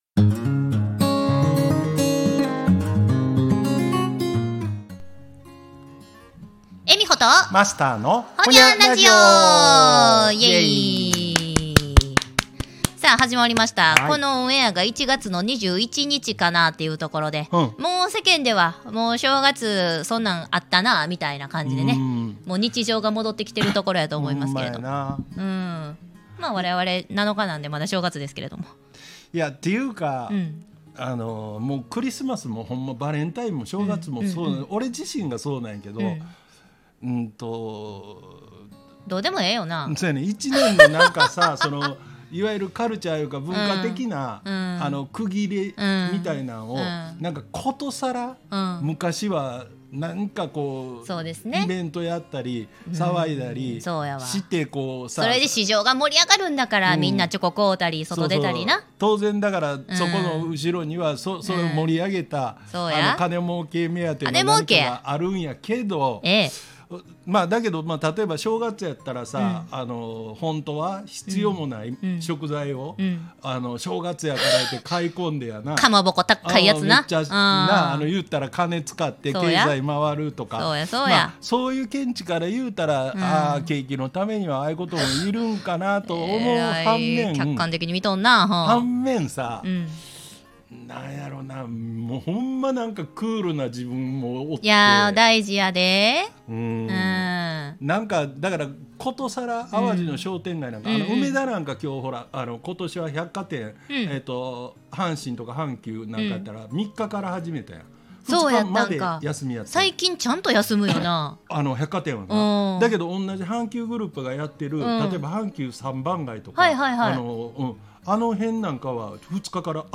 【大阪市内のスタジオライブバー・カノンにて収録配信】 かつて泉の広場にいた立ちんぼが、今では地上のラブホ街に？トー横と呼ばれる東京・歌舞伎町にて密かに社会問題となっている、若者達の風紀の乱れ。